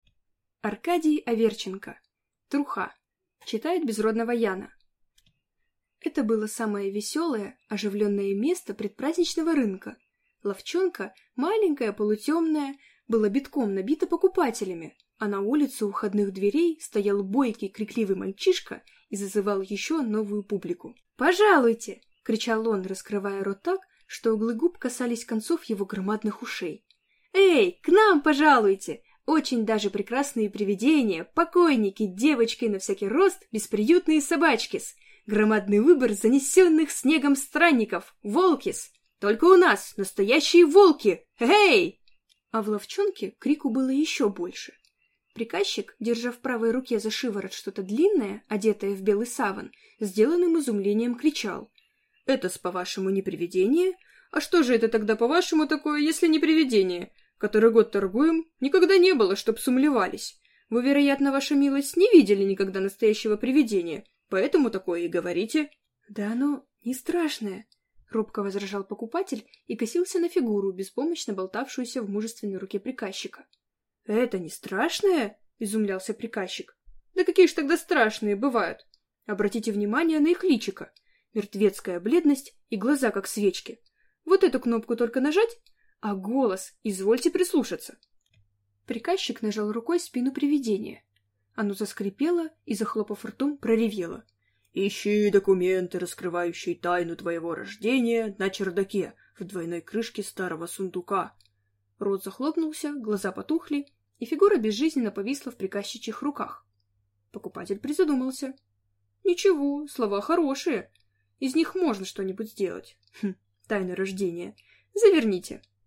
Аудиокнига Труха | Библиотека аудиокниг